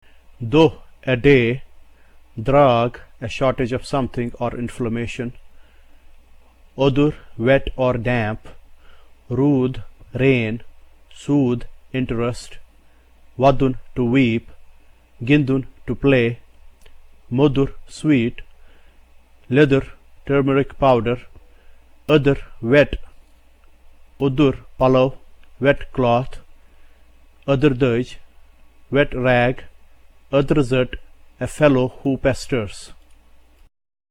The symbol D, d is used to identify the sound associated with the pronunciation of the letters TH in the English word THEN.